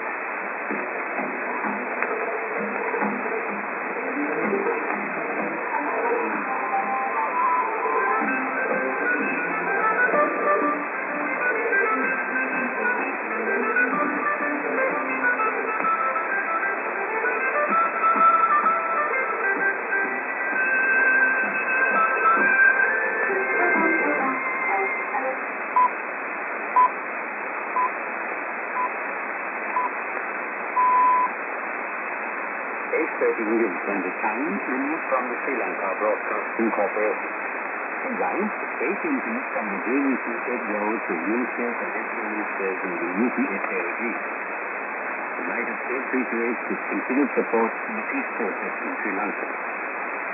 music->TS->ANN(man:ID)->